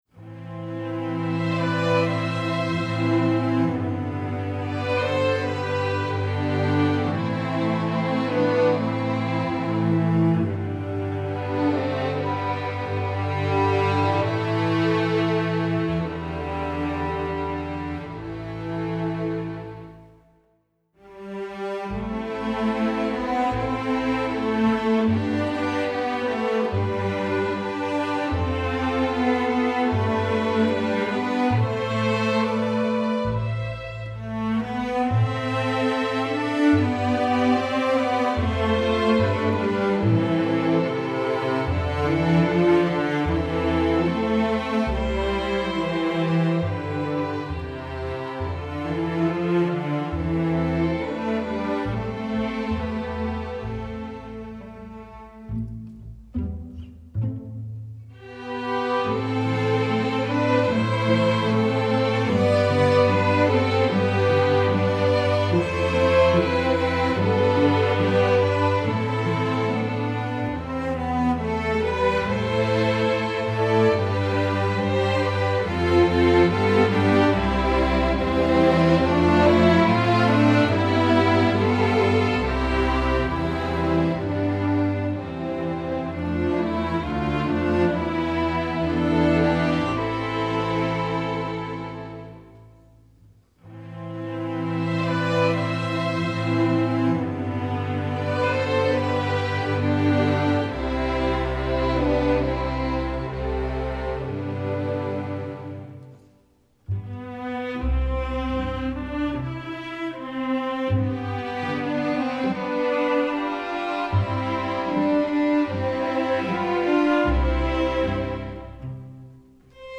Composer: English Folk Song
Voicing: String Orchestra